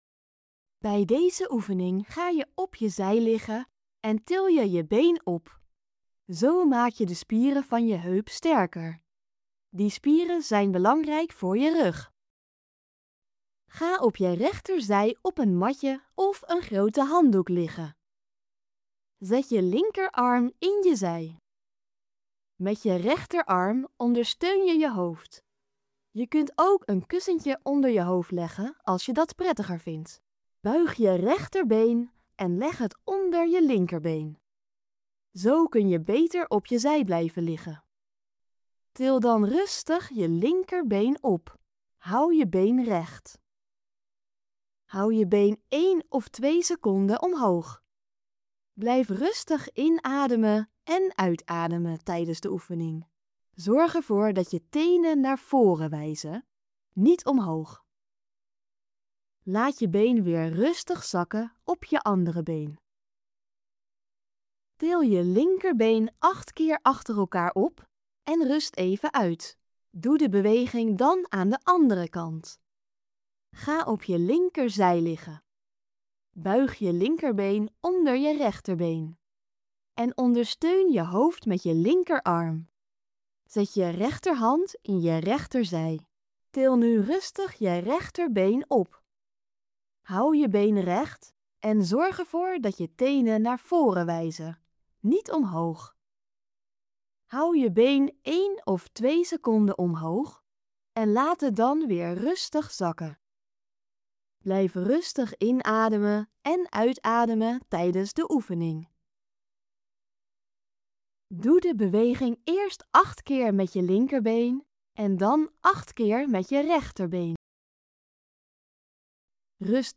Geluidsbestand voor als je slecht ziet of blind bent